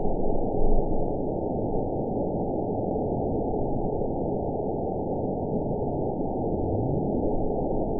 event 920061 date 02/21/24 time 02:40:35 GMT (2 months, 1 week ago) score 9.08 location TSS-AB09 detected by nrw target species NRW annotations +NRW Spectrogram: Frequency (kHz) vs. Time (s) audio not available .wav